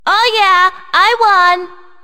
One of Princess Daisy's voice clips in Mario Party 6